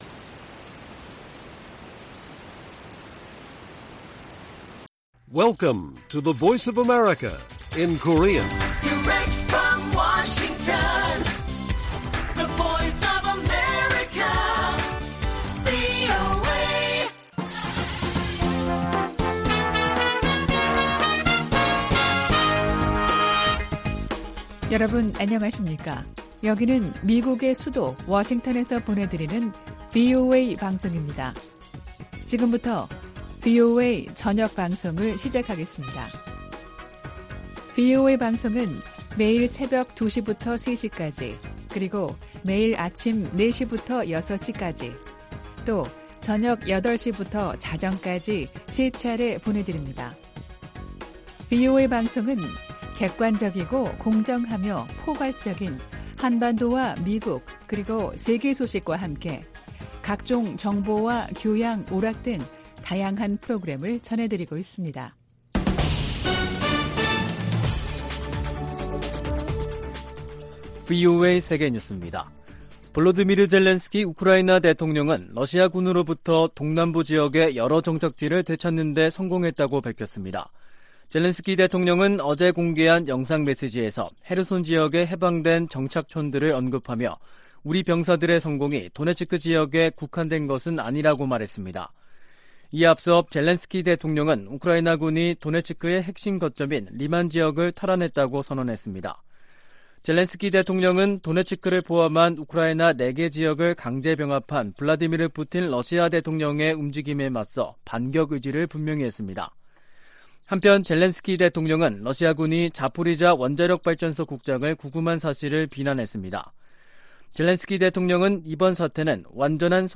VOA 한국어 간판 뉴스 프로그램 '뉴스 투데이', 2022년 10월 3일 1부 방송입니다. 북한이 지난 1일 동해상으로 탄도미사일(SRBM) 2발을 발사해 지난 달 25일 이후 총 7발의 미사일을 발사했습니다. 미 국무부는 잇따른 탄도미사일 발사로 안정을 흔드는 북한의 무기 역량을 제한하겠다는 의지를 나타냈습니다. 한국 탈북자 그룹이 또 신종 코로나바이러스 감염증 의약품 등을 매단 대형 풍선을 북한으로 보낸 것으로 알려졌습니다.